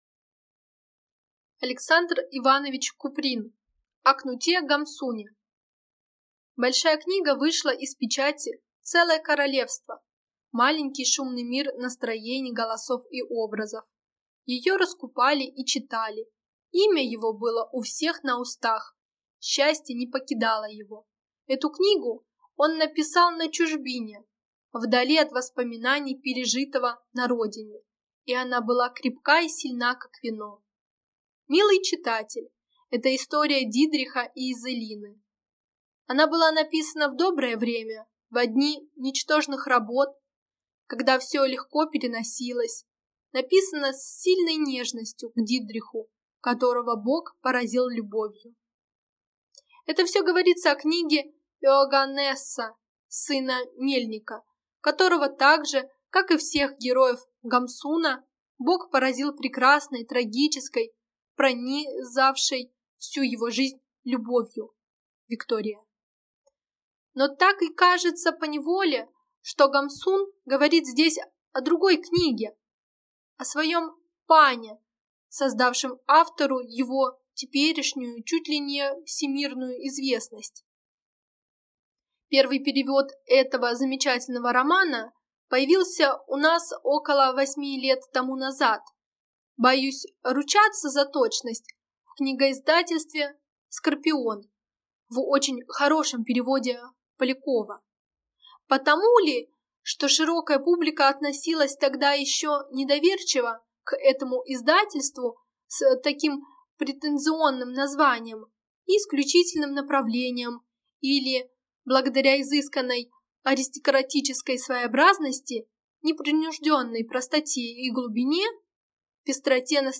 Аудиокнига О Кнуте Гамсуне | Библиотека аудиокниг